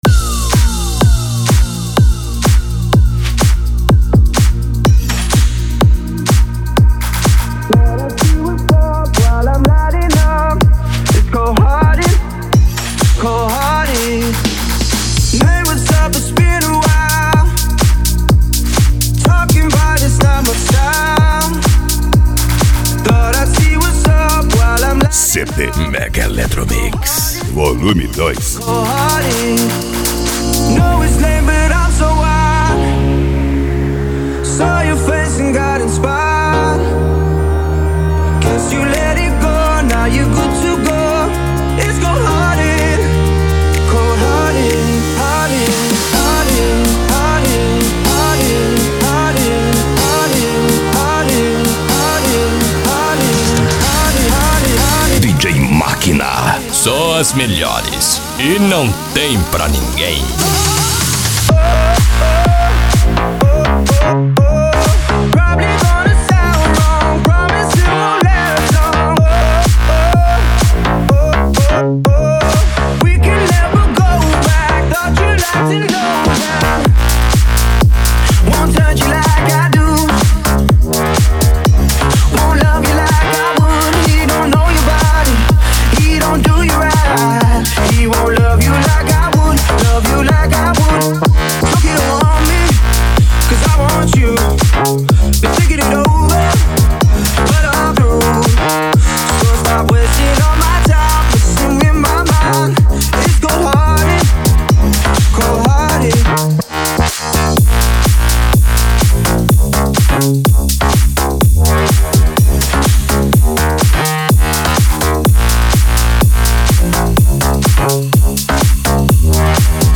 Electro House
Minimal
Psy Trance
Remix